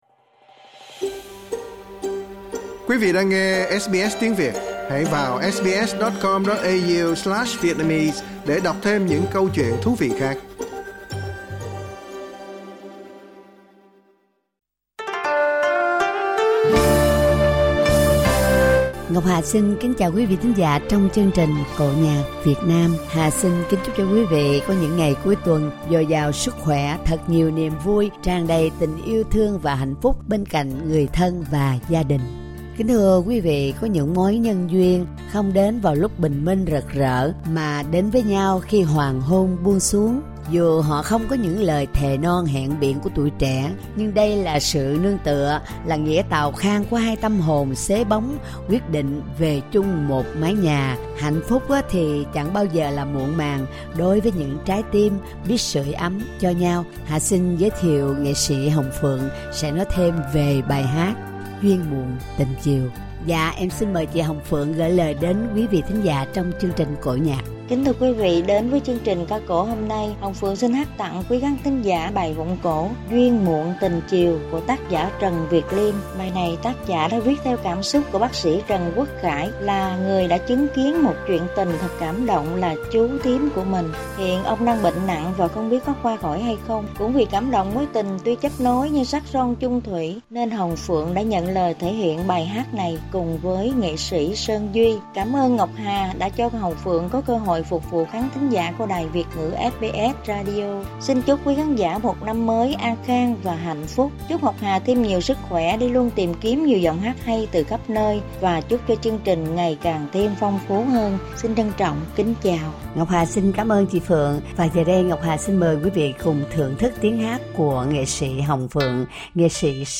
8 câu Nam xuân